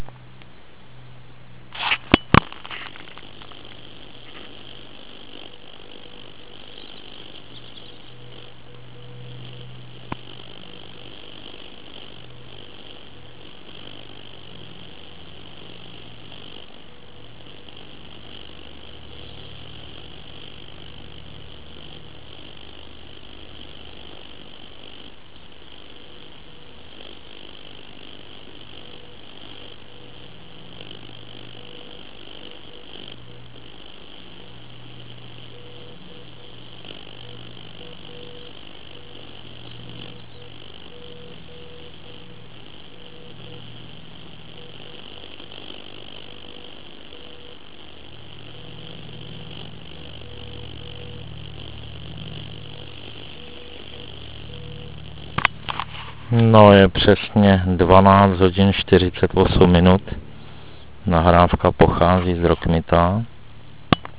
V mém QTH je spuštěn maják 500 mW ( předpokládaný výkon Rozkmita).
A tak v nahrávkách je možno rozeznat ptačí zpěv. Dosti mne i překvapilo, kde se v lese bere tolik rušení.
Nahrávka z Rozkmita (260 KB)
Nahrávky jsou to děsivé.